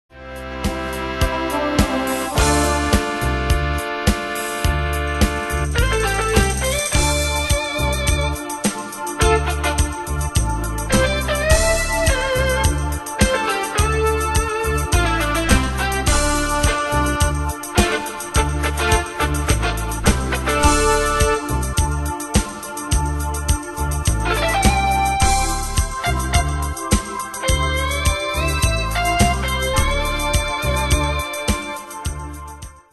Demos Midi Audio
Style: Rock Année/Year: 1987 Tempo: 105 Durée/Time: 5.18
Danse/Dance: Rock Cat Id.
Pro Backing Tracks